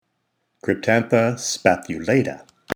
Pronunciation/Pronunciación:
Cryp-tán-tha spa-thu-là-ta